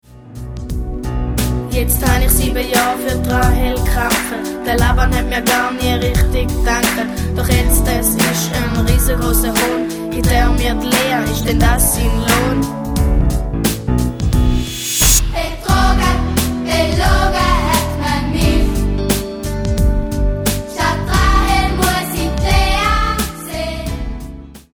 Ruhige Balladen und fetzige Popsongs wechseln sich ab.
Musical-CD mit Download-Code